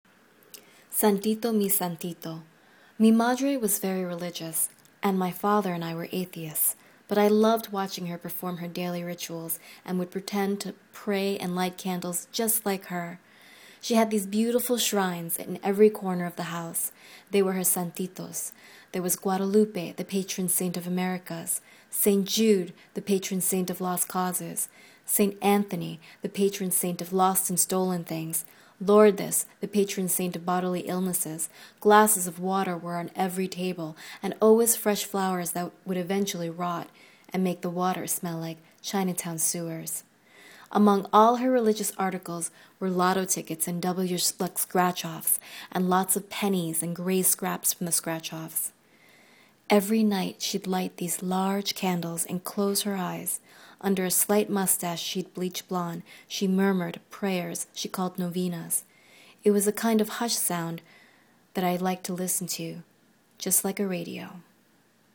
Under a slight mustache she’d bleach blonde, she murmured prayers she called novenas. It was a kind of hushed sound that I liked to listen to like a radio.